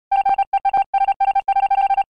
Among Us Typing